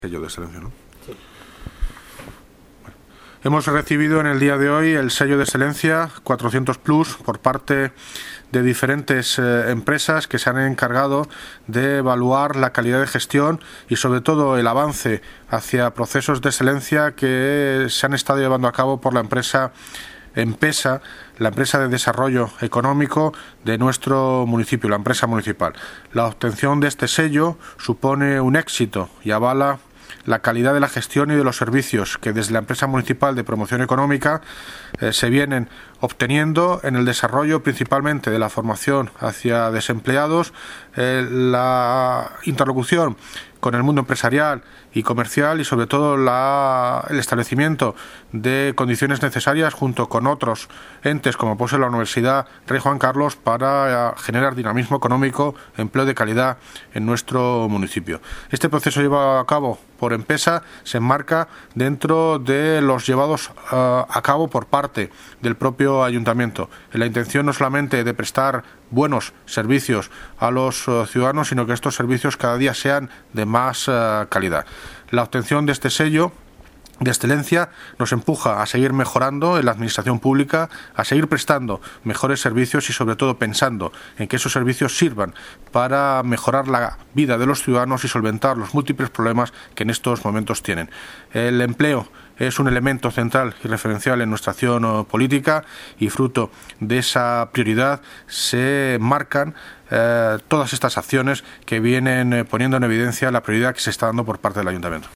Audio - David Lucas (Alcalde de Móstoles) Sobre Sello de Excelencia